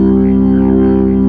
55o-org01-C1.wav